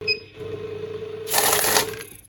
Cash Counting Machine
Cash-Counting-Machine-–-Counting-A-Few-Notes-Sound-Effects-Download-.mp3